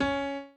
b_pianochord_v100l16-5o5cp.ogg